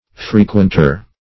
Frequenter \Fre*quent*er\, n.